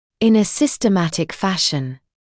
[ɪn ə ˌsɪstə’mætɪk ‘fæʃn][ин э ˌсистэ’мэтик ‘фэшн]систематическим образом